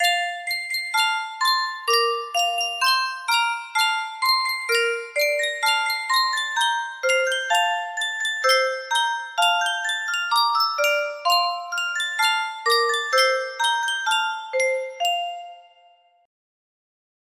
Sankyo Music Box - Waltzing Matilda 6R music box melody
Full range 60